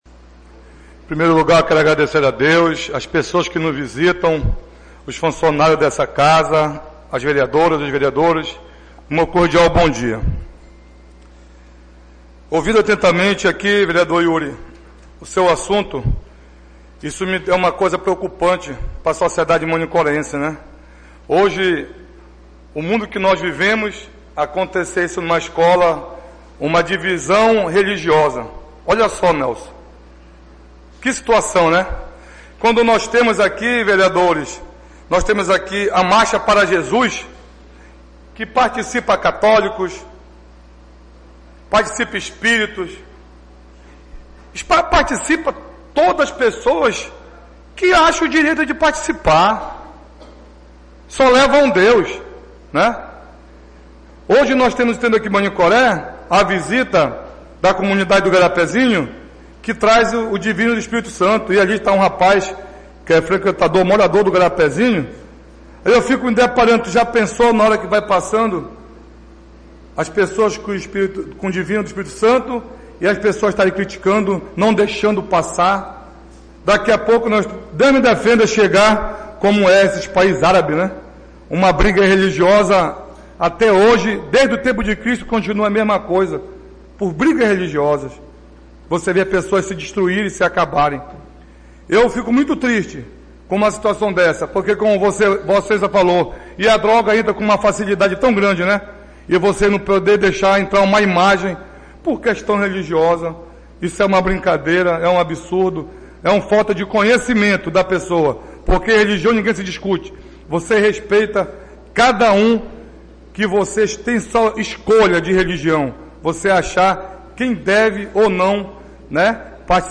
Oradores do Expediente (29ª Sessão Ordinária da 3ª Sessão Legislativa da 31ª Legislatura)